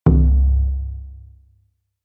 Surdo-5.mp3